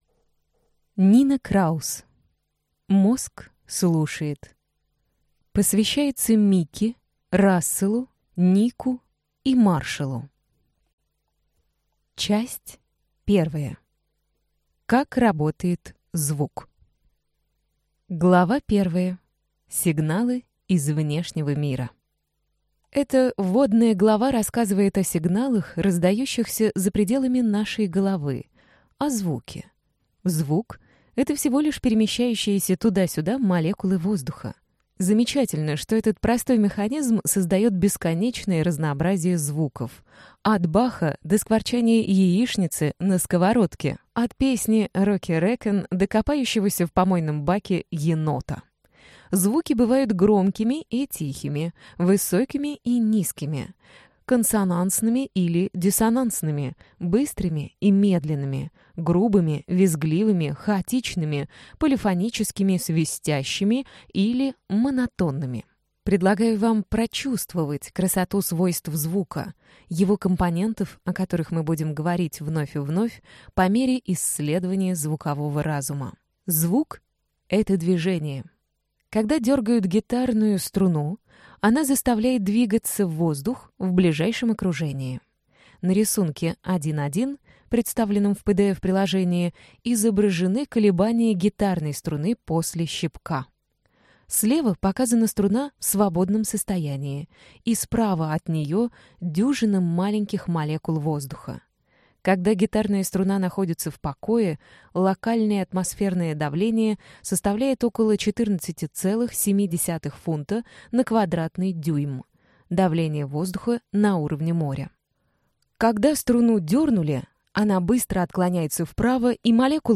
Аудиокнига Мозг слушает. Как создается осмысленный звуковой мир | Библиотека аудиокниг